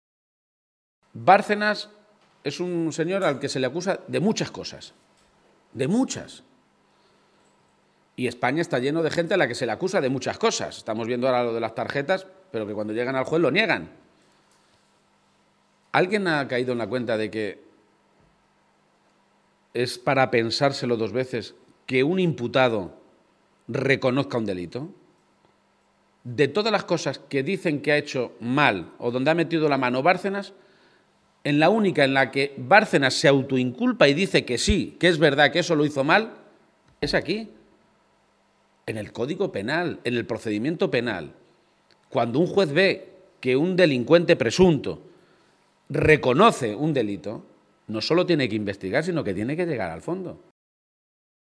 El candidato a Presidente de Castilla-La Mancha hacía estas declaraciones en una comparecencia ante los medios de comunicación en la ciudad minera, después de mantener una reunión con el Comité de Empresa de Elcogás, donde ha recibido información de primera mano de cómo está la negociación que están llevando a cabo tanto con el ministerio de industria, como con la propia empresa, que ha anunciado el cierre en diciembre si el Estado no cambia el marco que regula las ayudas públicas a este tipo de industrias energéticas.